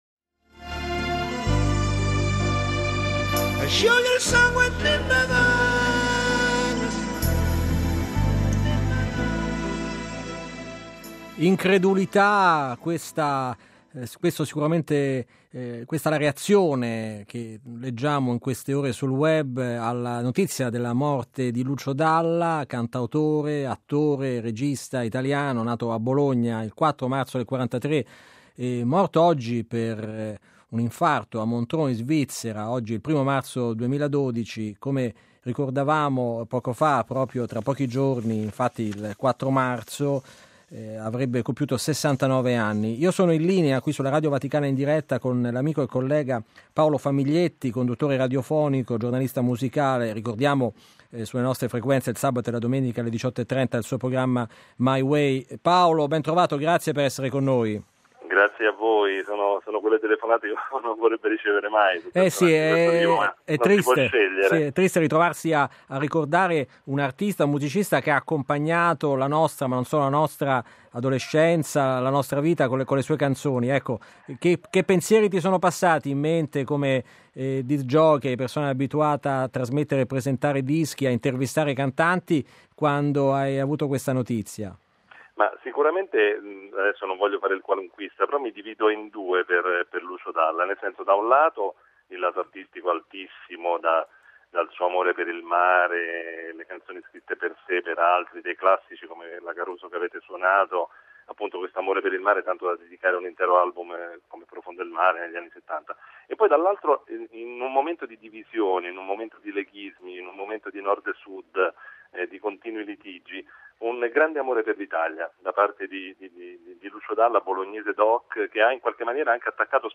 Ne emerge il ritratto di un artista generoso ed eclettico, un instancabile talent-scout che amava sperimentare, capace di lasciare la sua impronta su quasi mezzo secolo di storia del Pop italiano. (Intervista